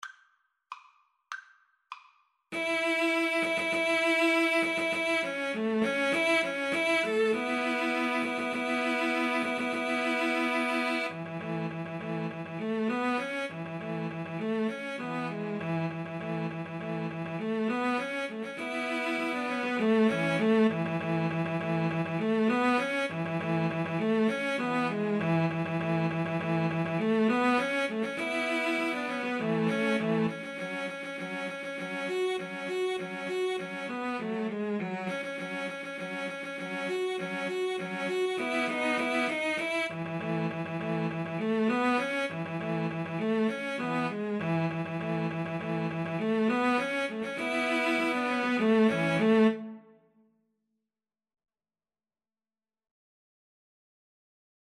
Play (or use space bar on your keyboard) Pause Music Playalong - Player 1 Accompaniment Playalong - Player 3 Accompaniment reset tempo print settings full screen
A major (Sounding Pitch) (View more A major Music for Cello Trio )
Presto =200 (View more music marked Presto)